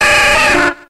Grito de Squirtle.ogg
Grito_de_Squirtle.ogg.mp3